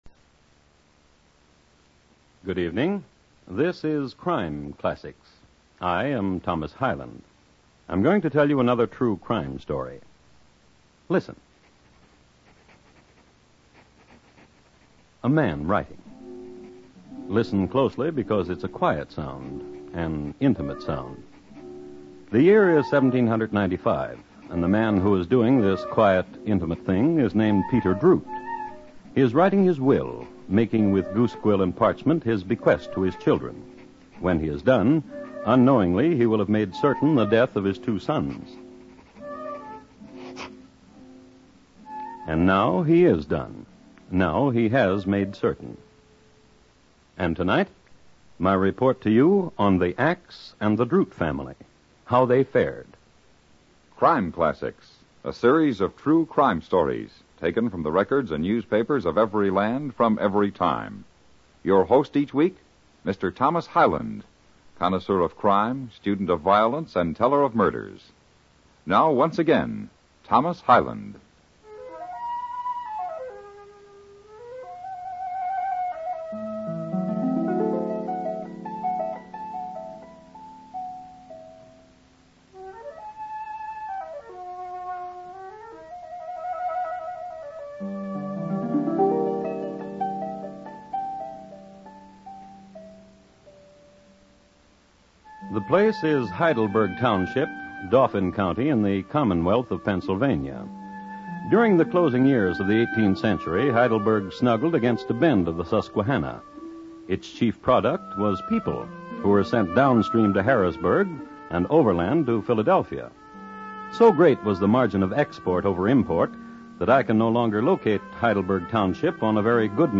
Crime Classics Radio Program, Starring Lou Merrill